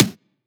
edm-snare-62.wav